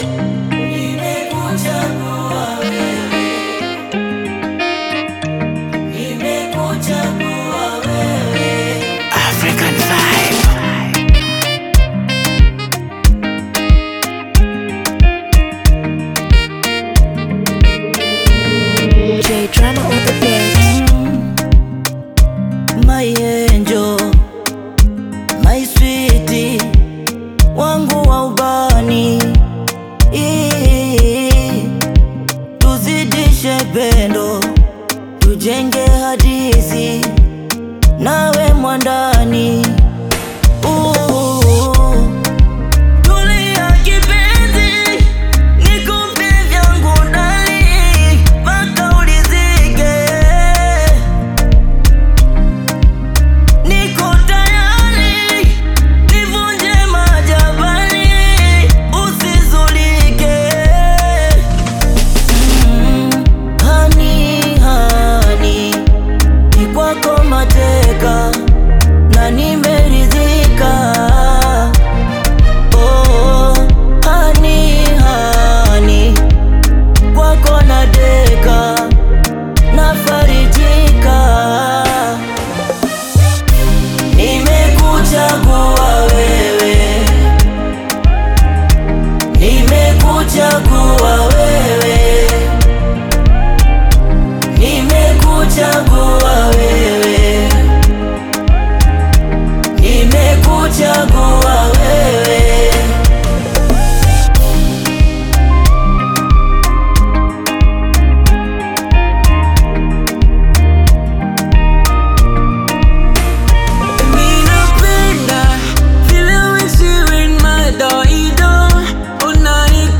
smooth Tanzanian Bongo Flava/Afro-Pop collaboration
catchy Afro-inspired rhythms
Latest Bongo Flava, Afrobeat and more updates 🔥